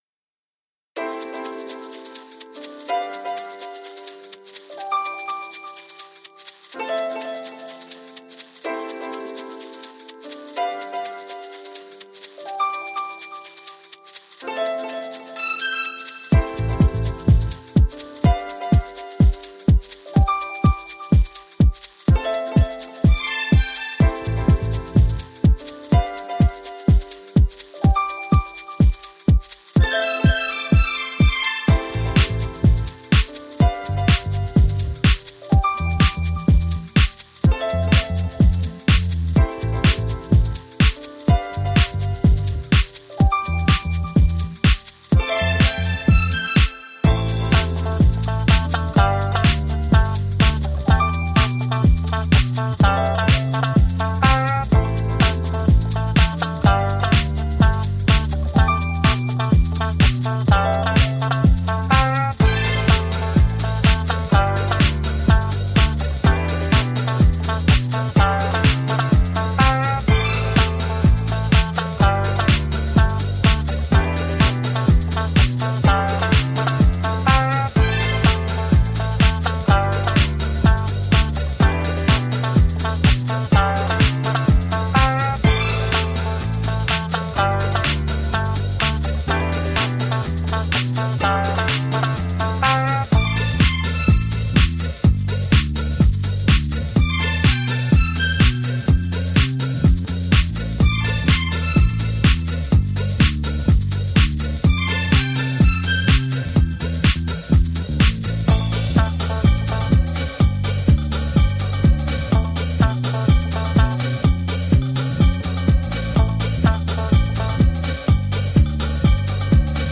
Jazzy eclectic and elegant dancefloor friendly electronica.
Tagged as: Jazz, Electronica, Chillout